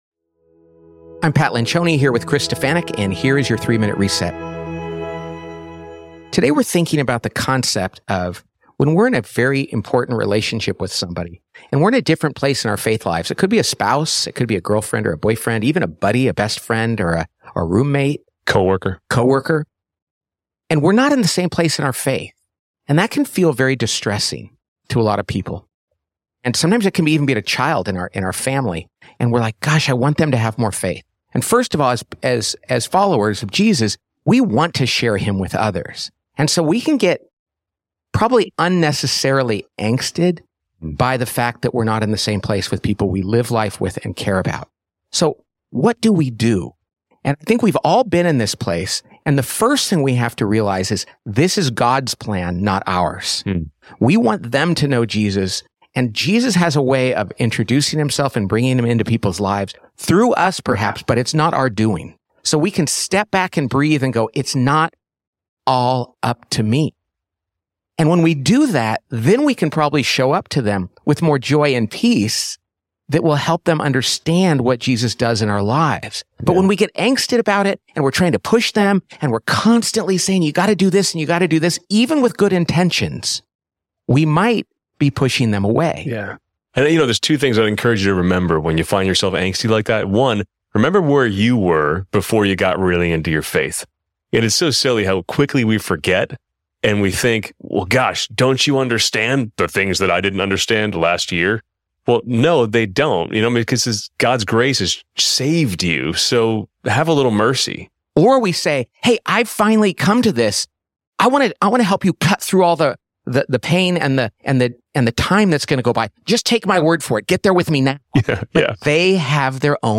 a daily reflection for Christians in the workplace.